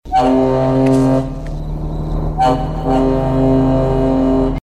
• LARGE BOAT HORN.wav
LARGE_BOAT_HORN_NYS.wav